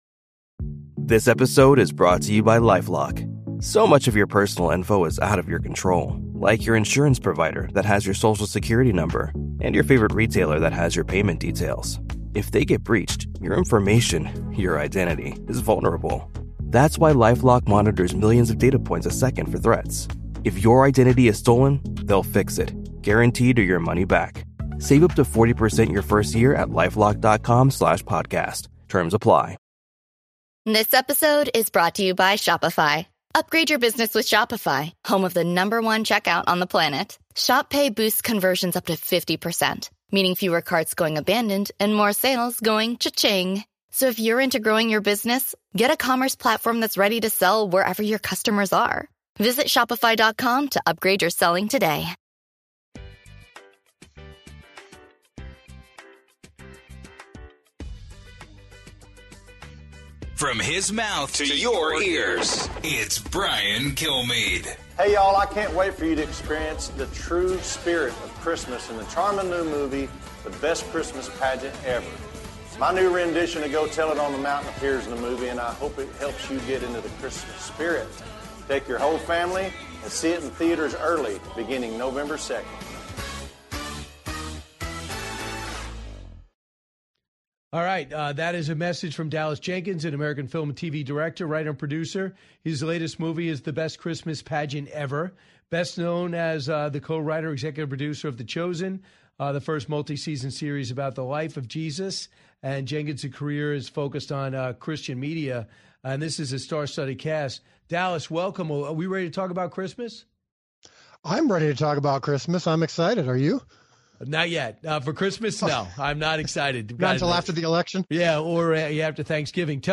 Director Dallas Jenkins On Studios Wanting More Religious Content In Movies Podcast with Brian Kilmeade